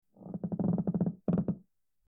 Floorboards Creaking
Floorboards_creaking.mp3